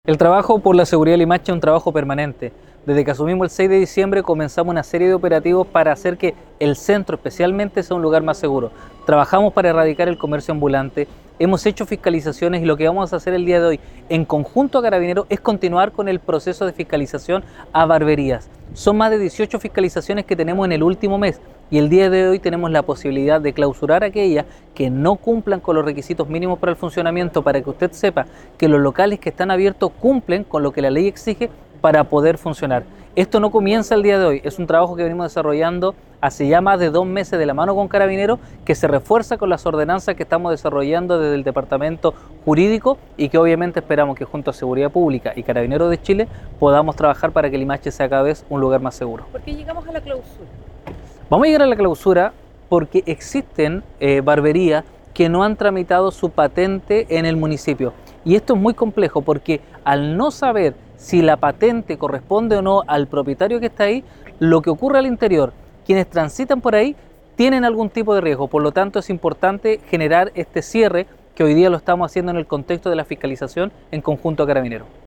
El alcalde de Limache, Luciano Valenzuela, recalcó el compromiso con el bienestar de vecinos, destacando que este es un trabajo que vienen realizando desde que asumió la jefatura comunal, donde solo en el último mes se han realizado 18 fiscalizaciones: